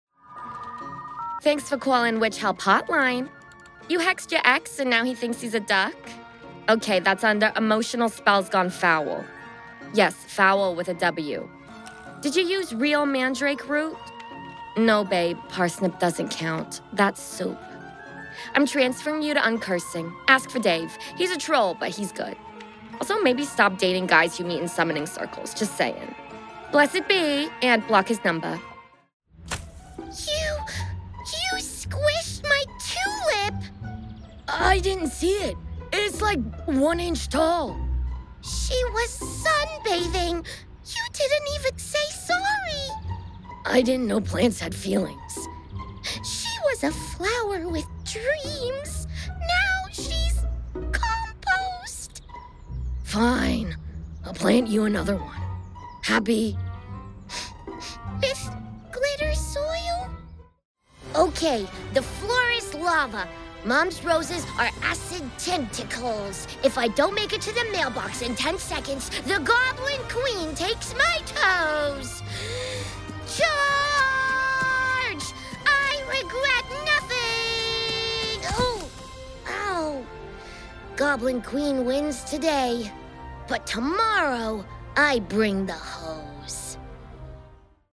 Character Reel
• Native Accent: Californian
• Home Studio